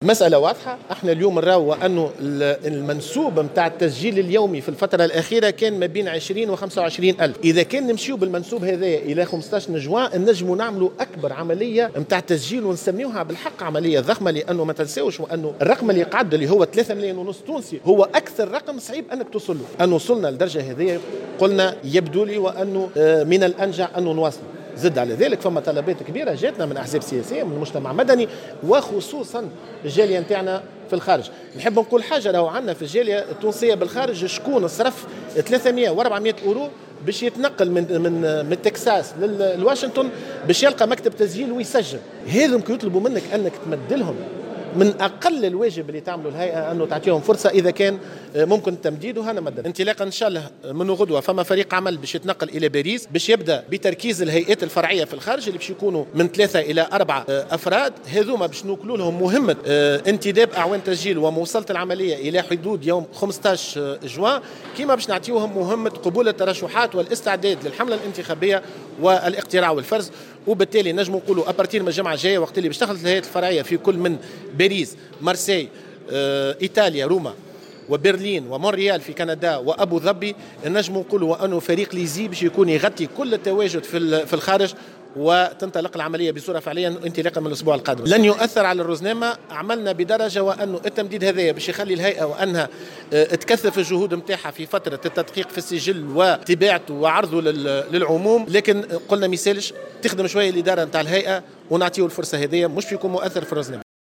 خلال ندوة صحفية